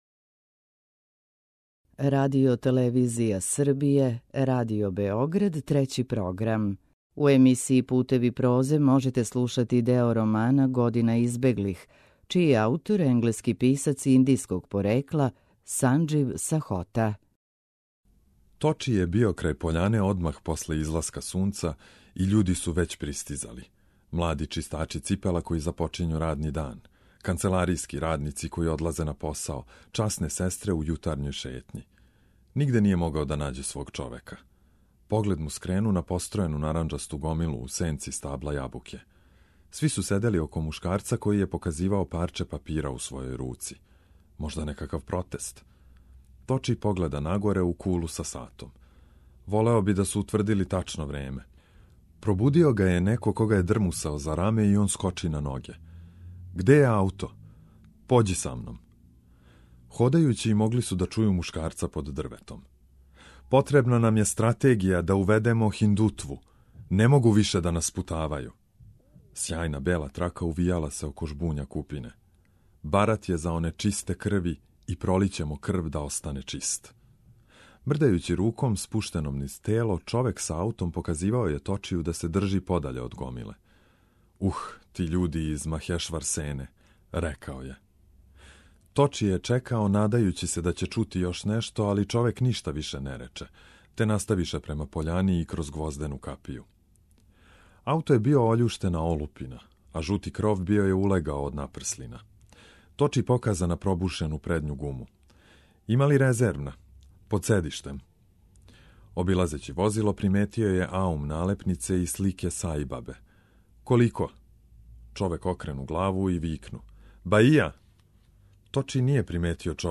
У циклусу Путеви прозе ове недеље можете слушати део романа „Година избеглих”, чији је аутор британски писац индијског порекла Санџив Сахота.